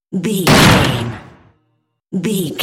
Cinematic stab hit trailer debris
Sound Effects
Atonal
heavy
intense
dark
aggressive